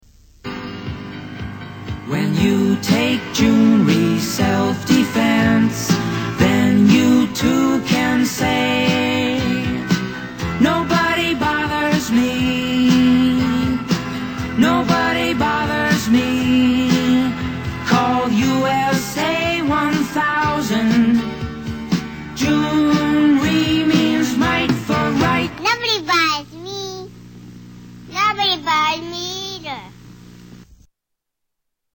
Tags: Television Awful Commericals Commercials Bad Commercial Media